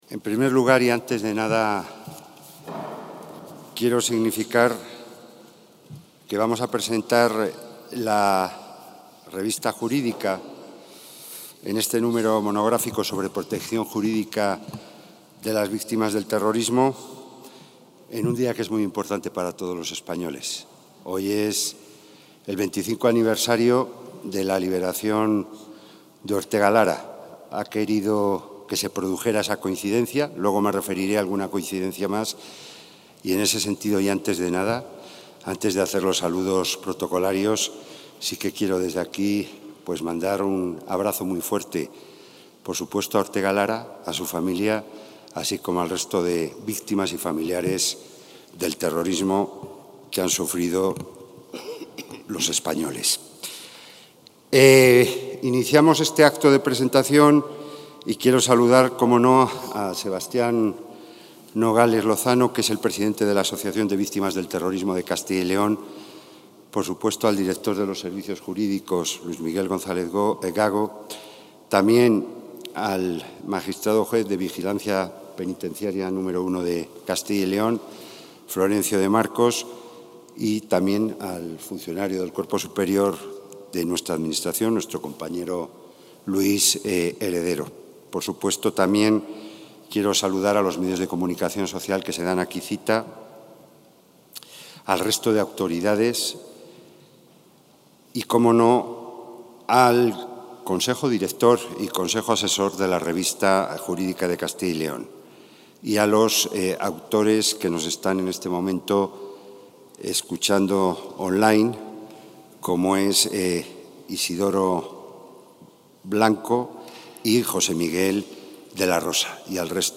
Intervención del consejero de la Presidencia.